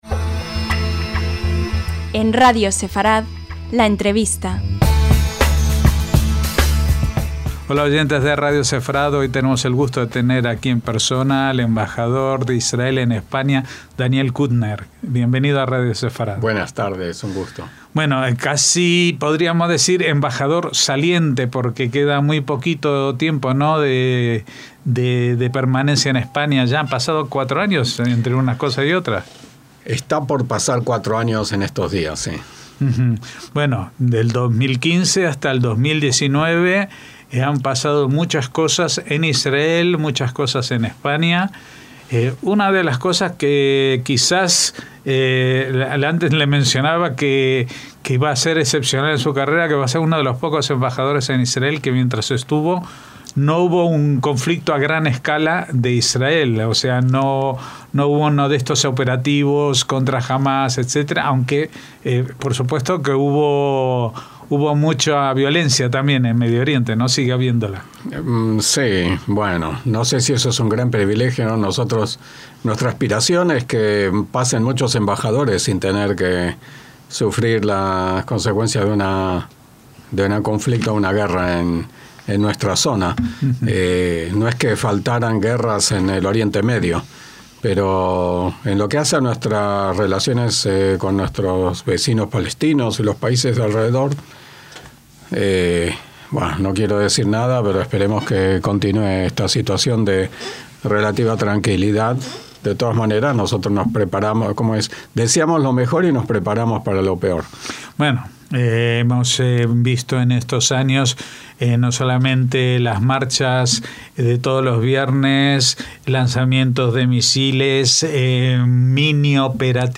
LA ENTREVISTA - En cuestión de semanas, el actual Embajador de Israel en España se despedirá de su cargo y retornará a Jerusalén.